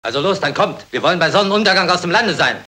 Sound file of German dubbing actor